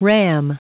Prononciation du mot ram en anglais (fichier audio)
Prononciation du mot : ram